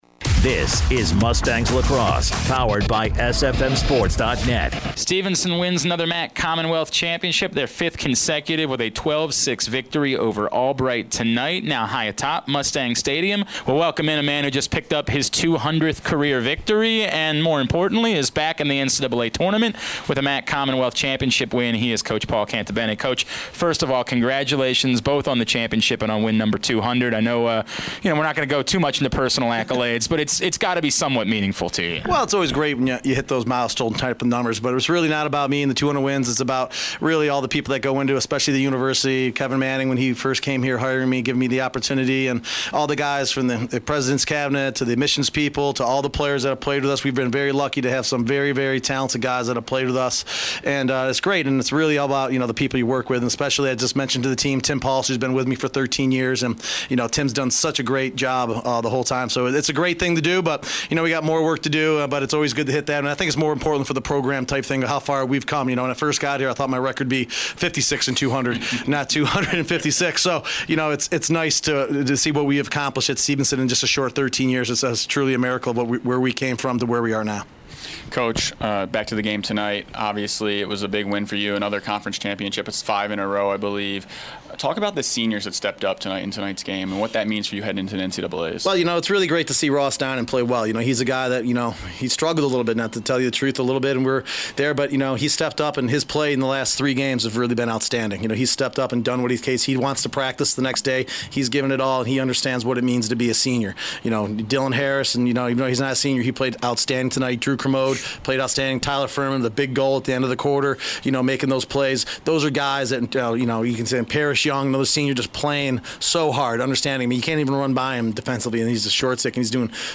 5/6/17: Stevenson Lacrosse Post Game Show